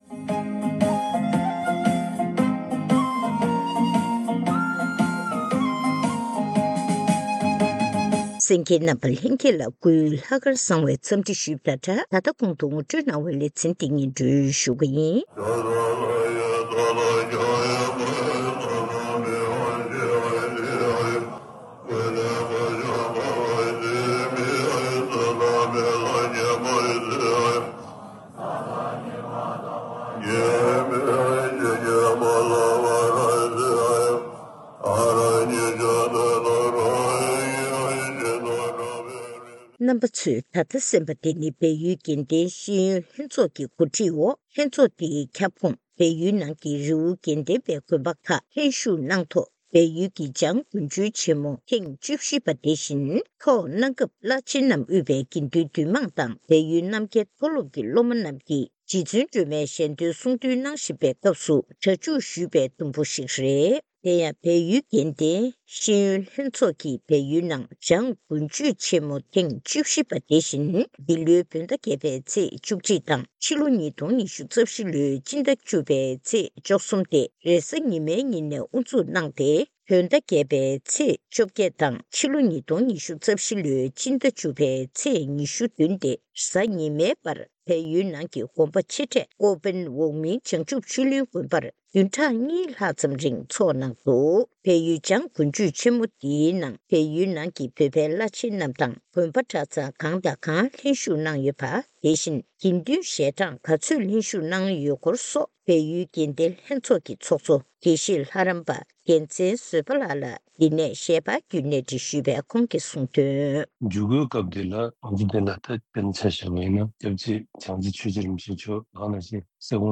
གསར་འགོད་པ།